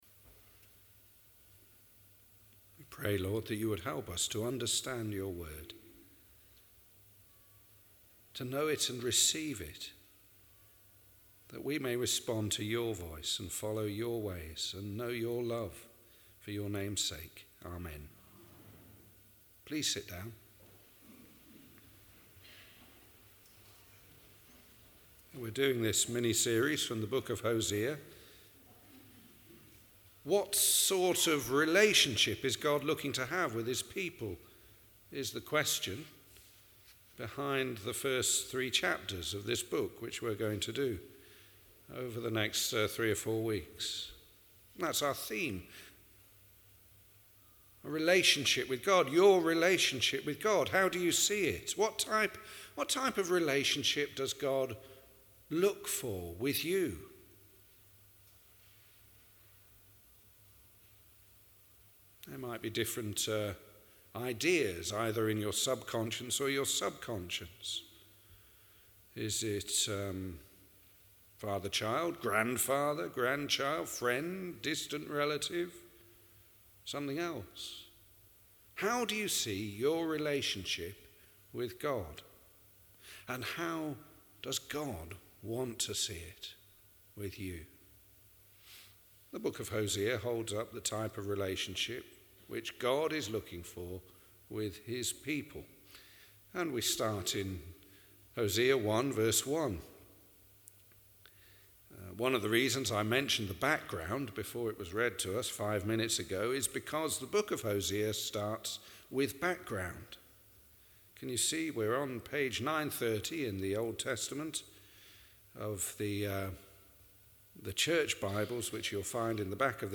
A short sermon series on the book of Hosea.